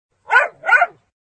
SDogAlarm.ogg